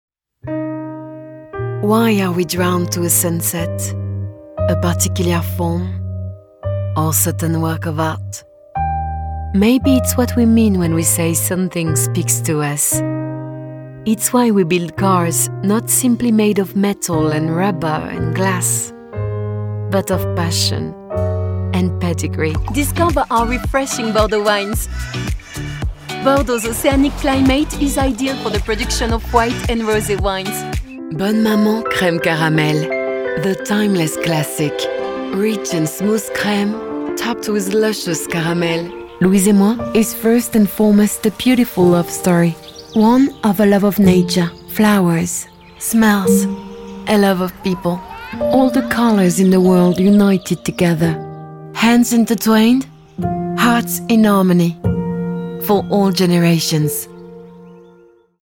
Commercial Demo
I am a professional French voice actress and singer,
equipped with a professional quality recording booth and would be delighted to work with you.
Mezzo-Soprano
WarmFunnyVersatile